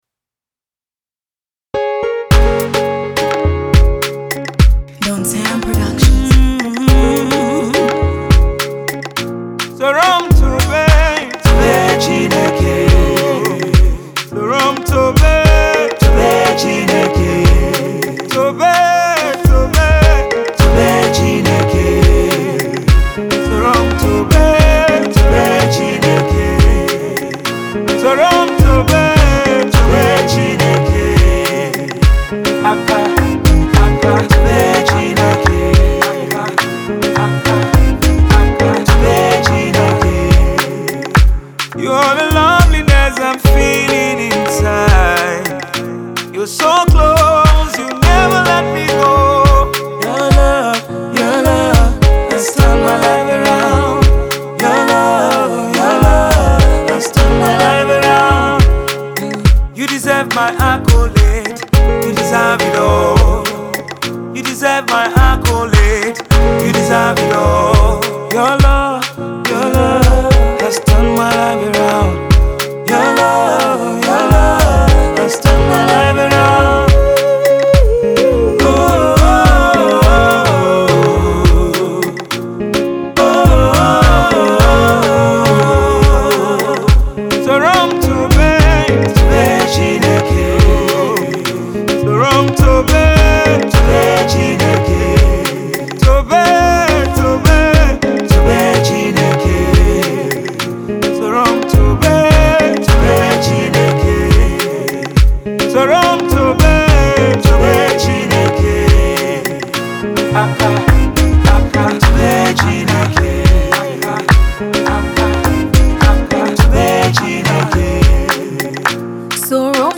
a beautiful eastern sound